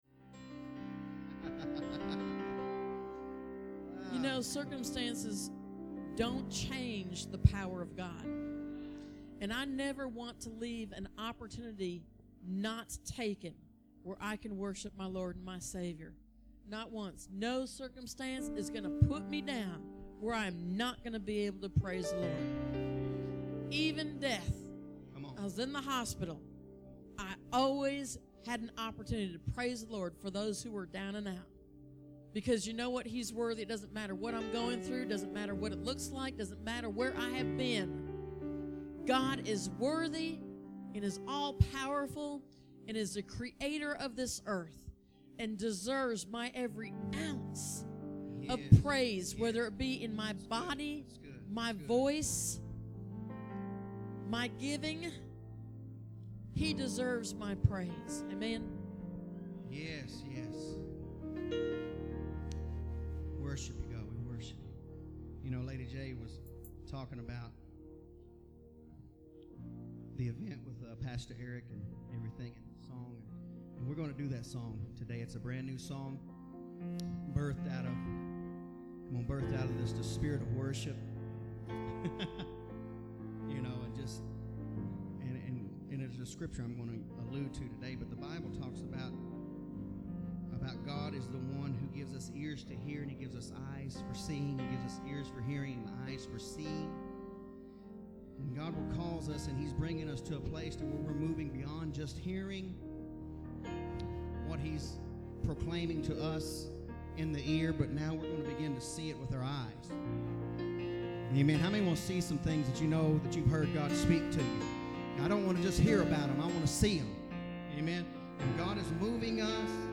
We Speak to Nations Worship
We Speak to Nations Praise and Worship from 2010